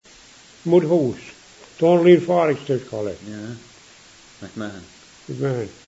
pronunciation.